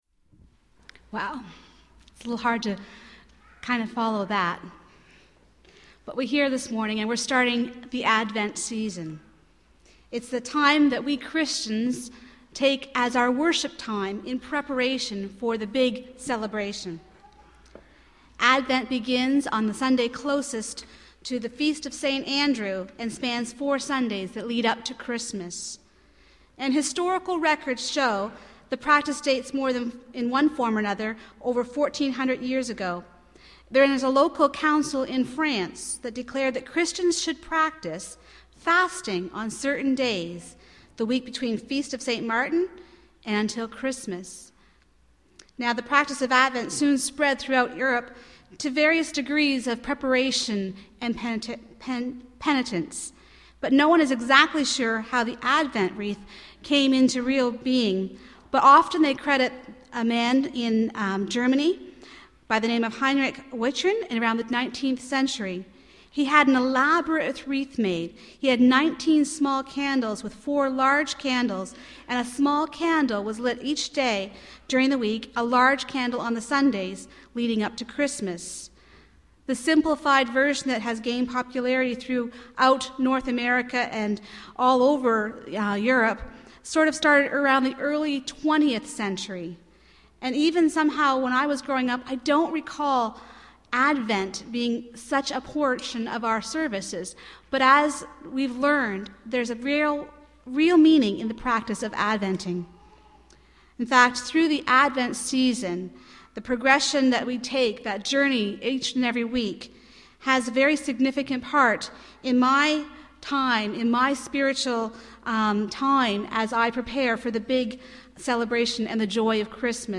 Reflection - Advent Reading and Candle Lighting.mp3